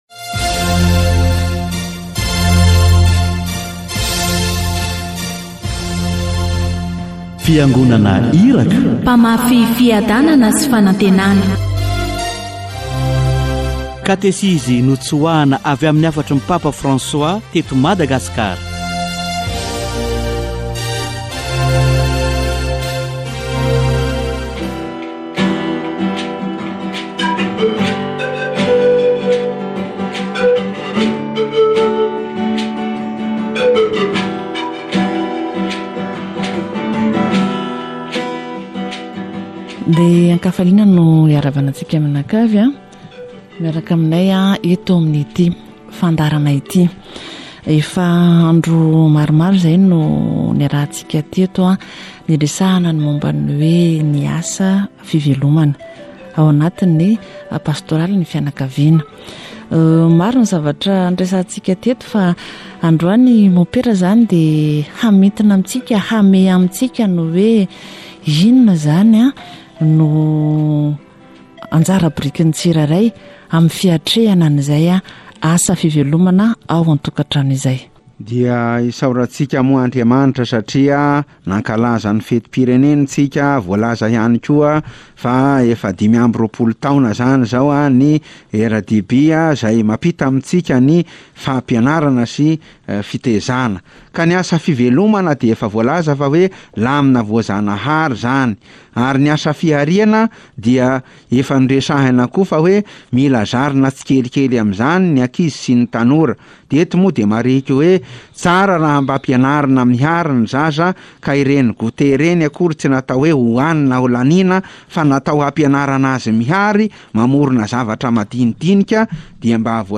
Tsy afaka hitondra vokatra ny fihaonana manokana amin'i Jesoa raha tsy ao anatin'ny ankohonana feno fiombonana. Katesizy momba ny asa fivelomana